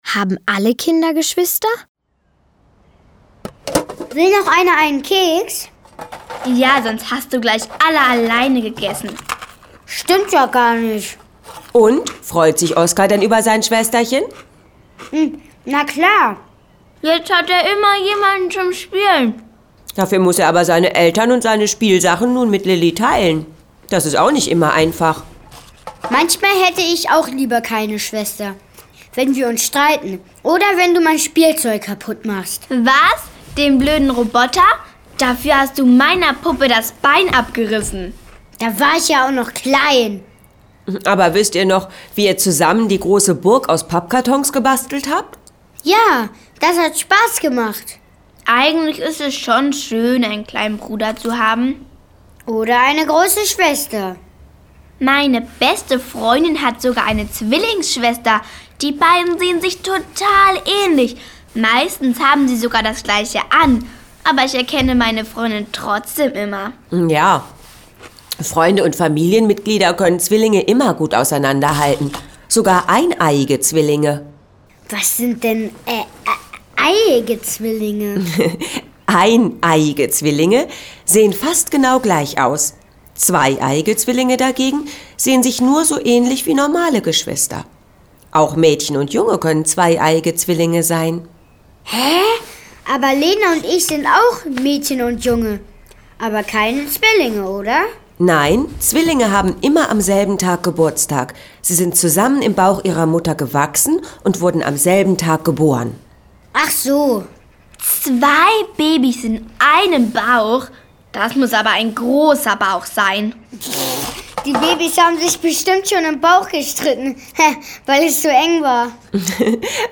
Schlagworte Familie; Kindersachbuch/Jugendsachbuch • Familienleben • Hörbuch; Hörspiel für Kinder/Jugendliche • Kinder/Jugendliche: Sachbuch: Orte & Menschen • Kinder/Jugendliche: Sachbuch: Orte & Menschen • Traditionen • Verwandtschaft